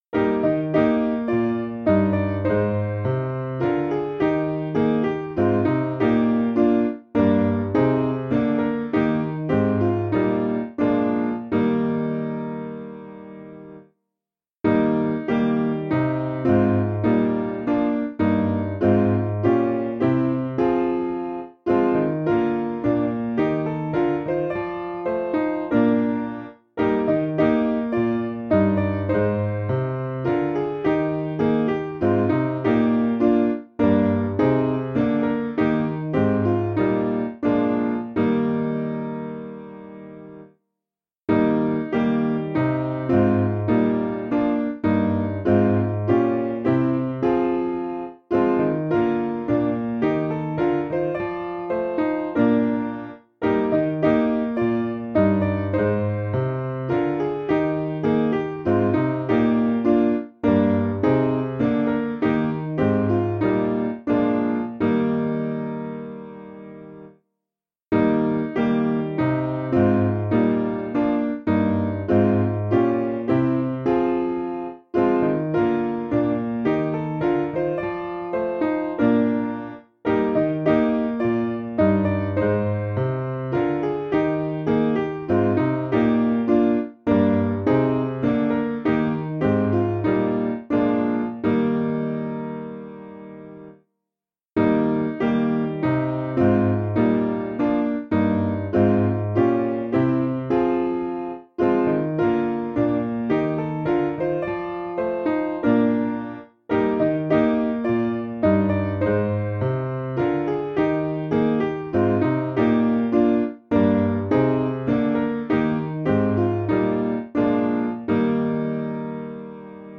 Information about the hymn tune CLIFTON (Brabham).
Meter: 6.6.8.6
Key: E♭ Major